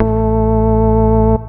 54_22_organ-A.wav